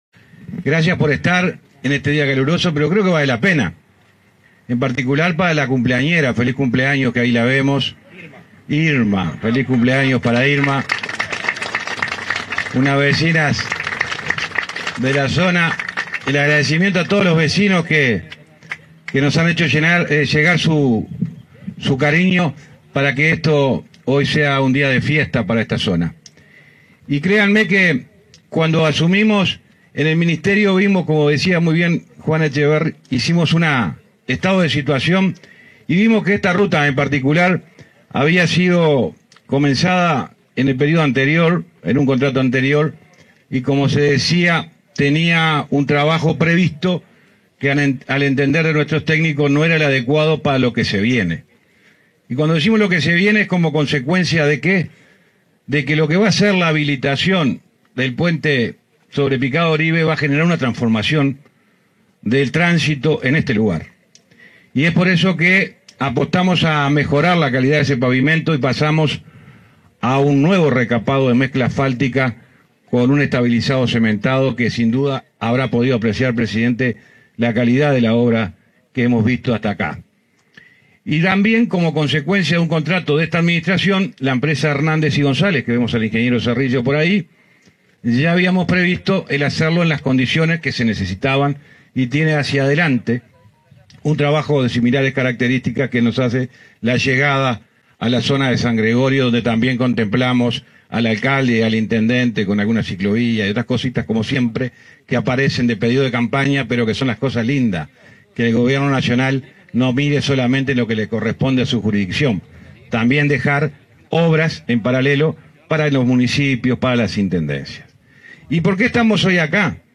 Palabras del ministro de Transporte y Obras Públicas, José Luis Falero, en inauguración de obras en cruce de rutas 43 y 59